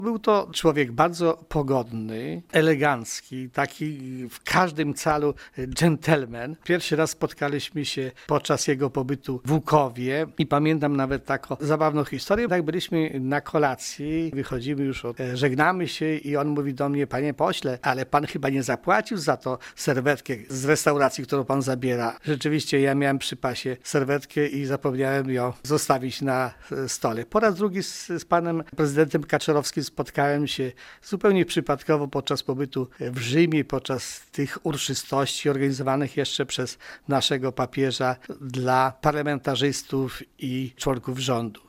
Wiktor Osik wspomina też ostatniego Prezydenta RP na uchodźstwie Ryszarda Kaczorowskiego: